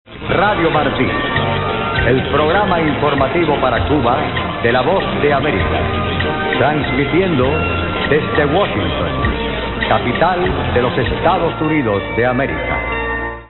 Here's what it sounded like when Radio Marti, after a hiatus, signed back on today on ...